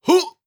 Effort Sounds
25. Effort Grunt (Male).wav